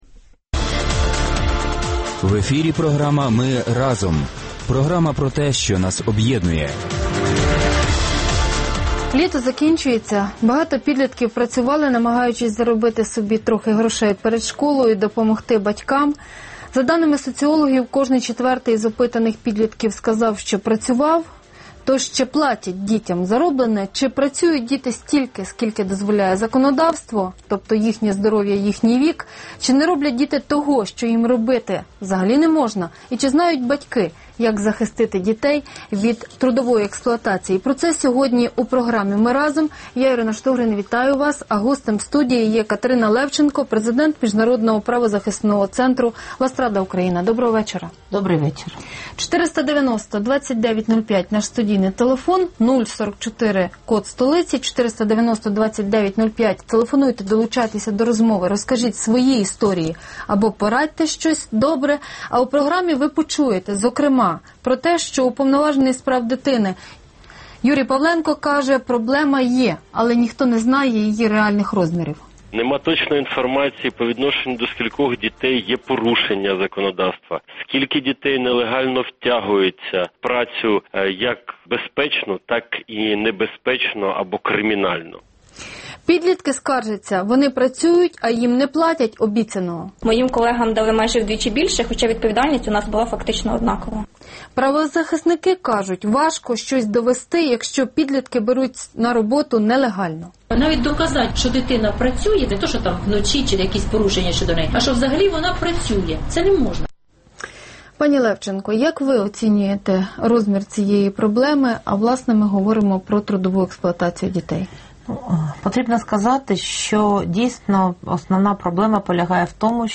Гість студії